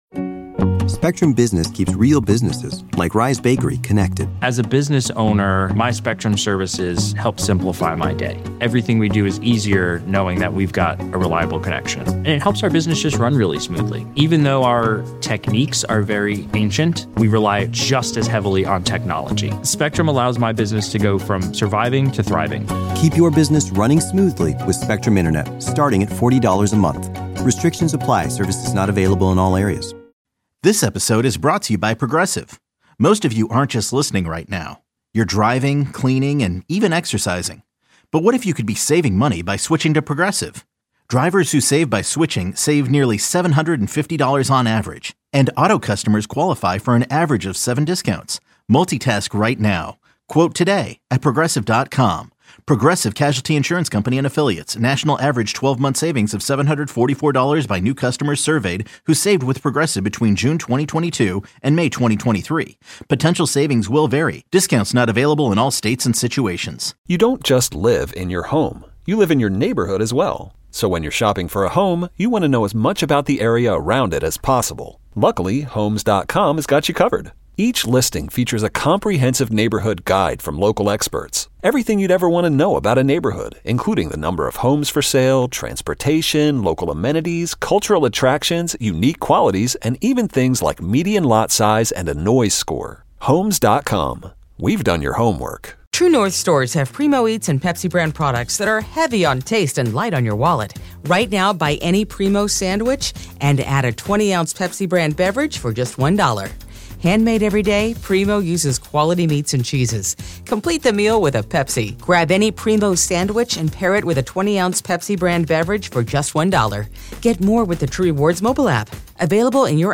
talk radio